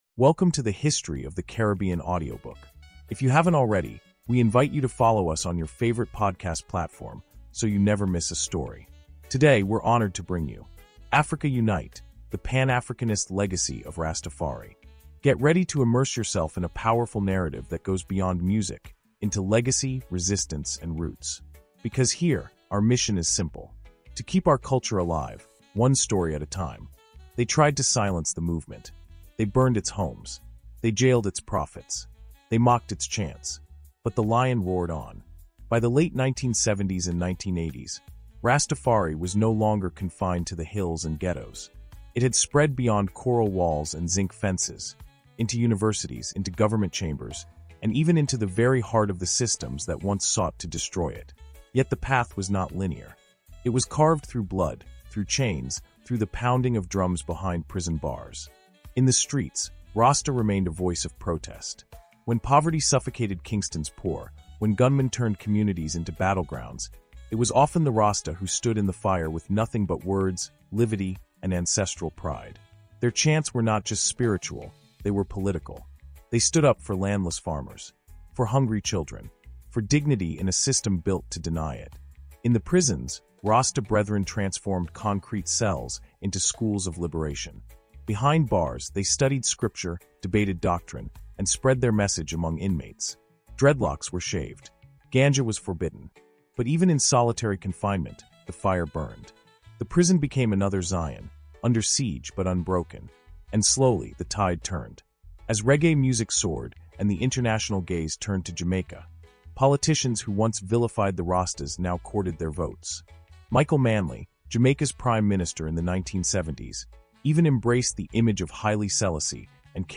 Africa Unite – The Pan-Africanist Legacy of Rastafari | Audiobook Insight